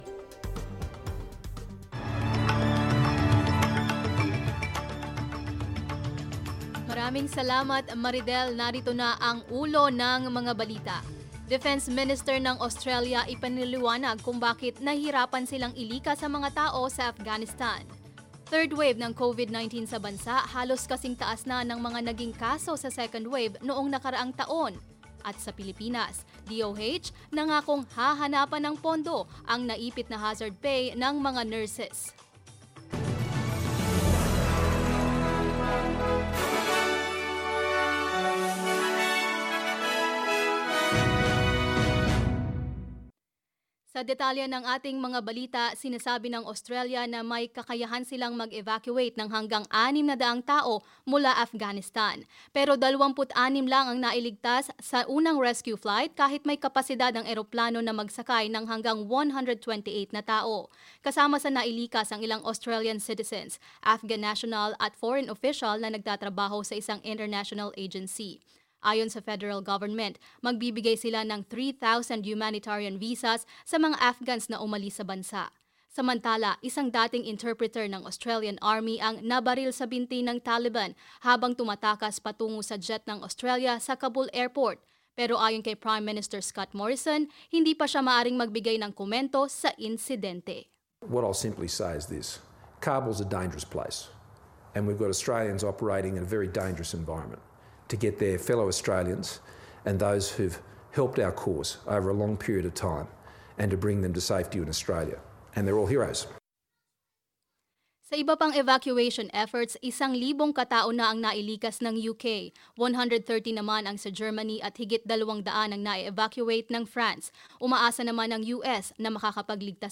SBS News in Filipino, Thursday 19 August